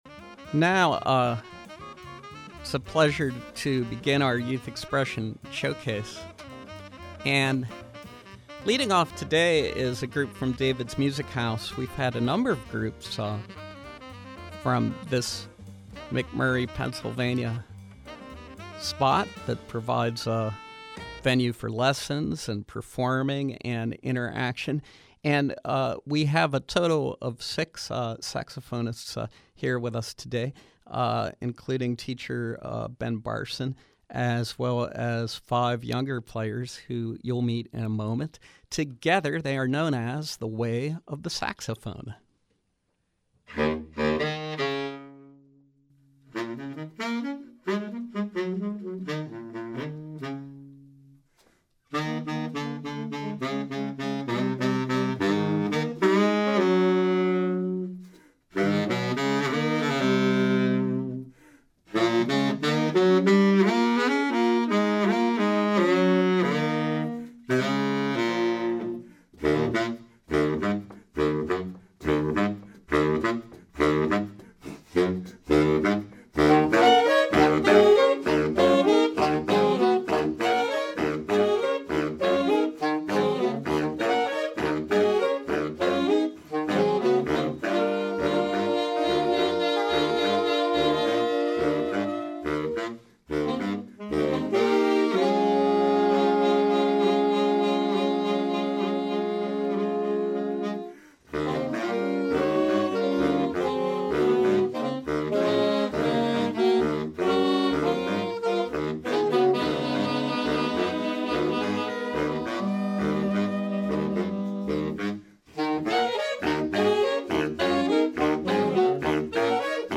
Sax ensemble